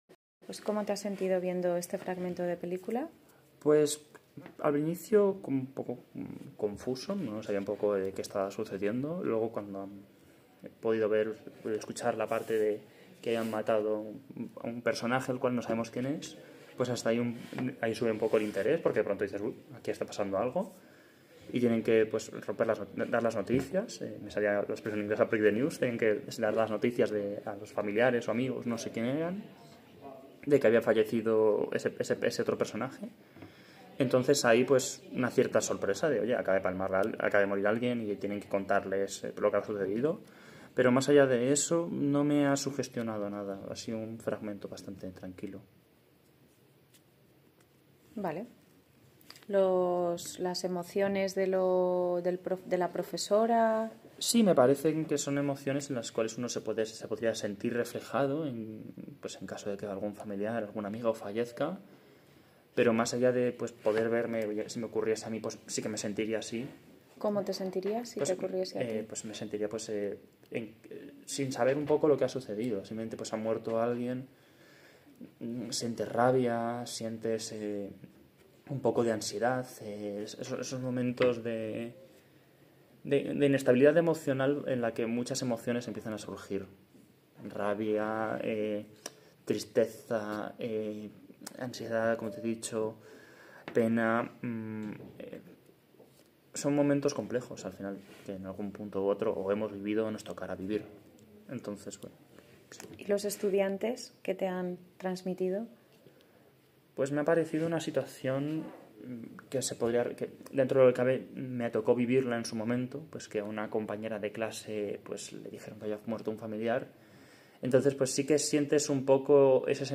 Corpus: Corpus de Expresión Emocional Español L1 variedad centro peninsular (CEEEL1)
Tipo de muestra: Oral
Tipo de texto: DIALÓGICO/MONOLÓGICO
Género de la muestra: INTERACCIÓN/NARRACIÓN